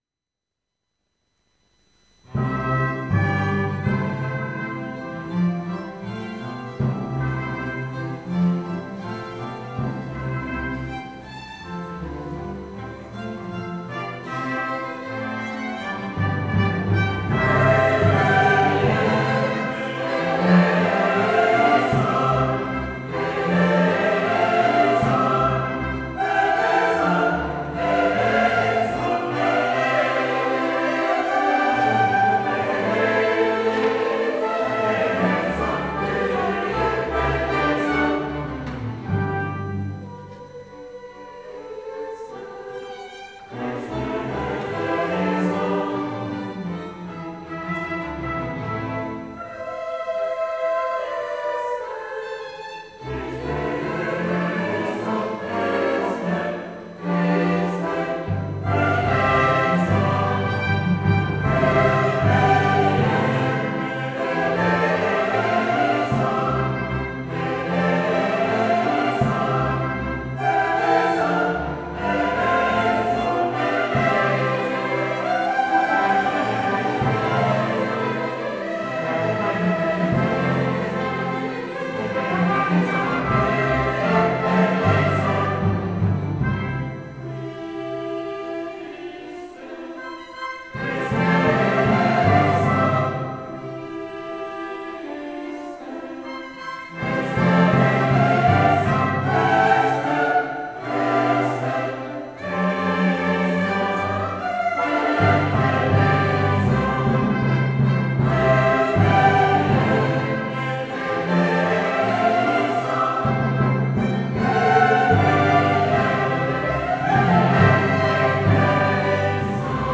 Ein Jahr nach der Weihe der St. Augustinuskirche wurde der gemischte Chor im Jahr 1927 gegründet.
Hier können Sie einen kleinen Einduck aus der Ostermesse 2025, der Spatzenmesse von W. A. Mozart erhalten.